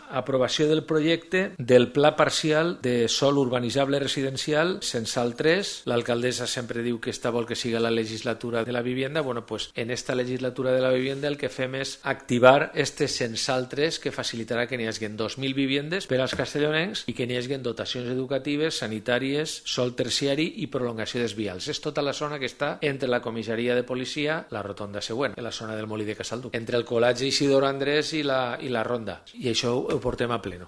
Corte de voz de Vicent Sales, portavoz del gobierno municipal.